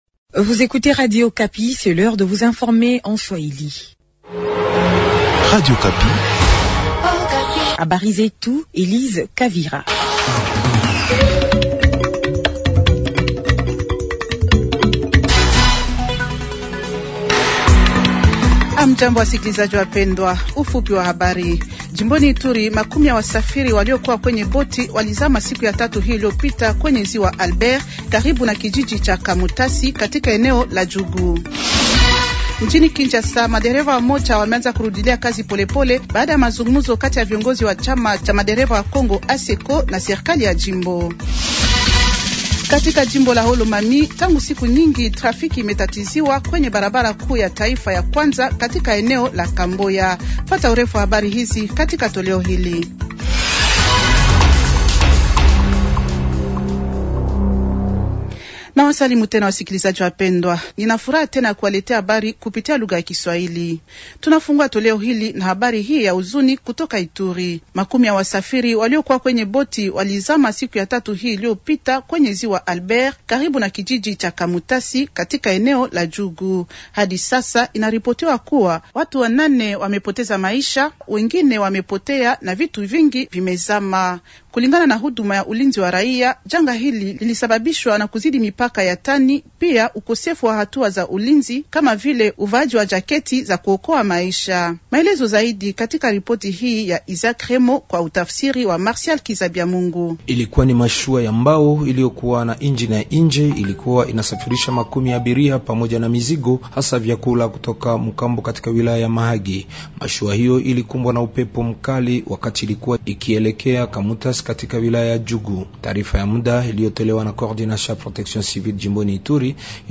Journal Swahili de jeudi soir 190326